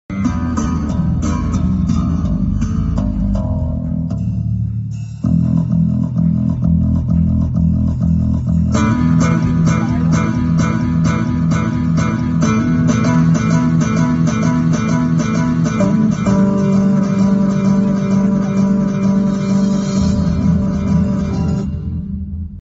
powerful 12 inch line array sound effects free download